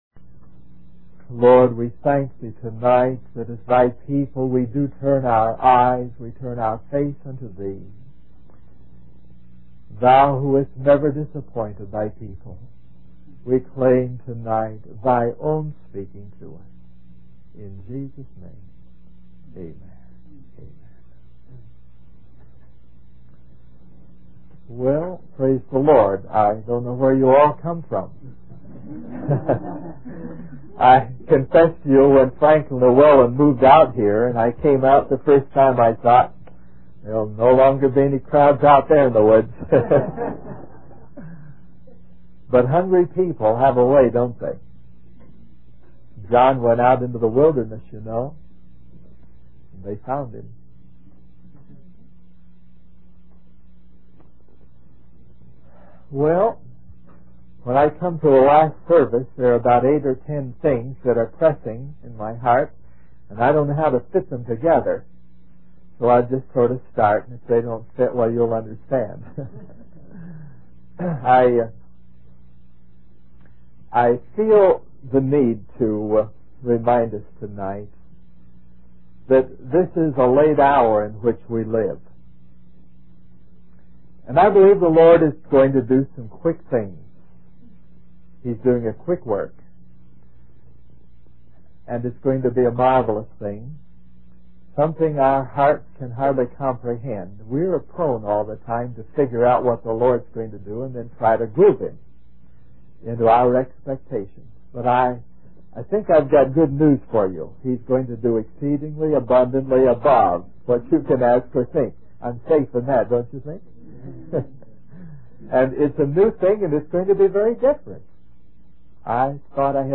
We apologize for the poor quality audio